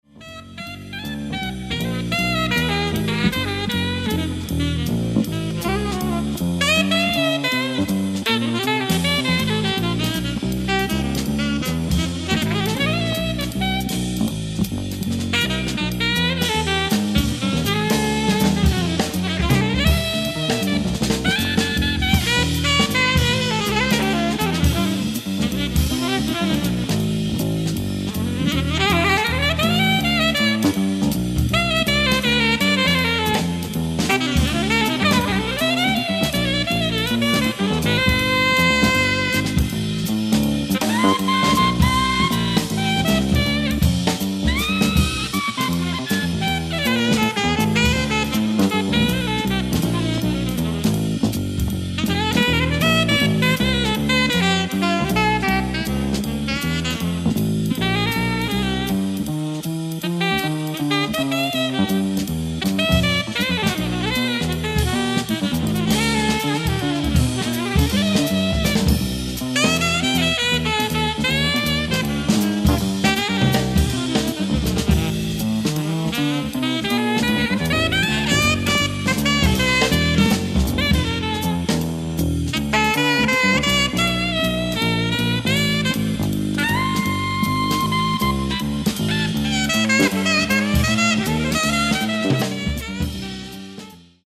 ライブ・アット・アリオスト劇場、レッジョ・ネレミリア、イタリア 03/29/1989
極上ステレオ・サウンドボード収録！！
※試聴用に実際より音質を落としています。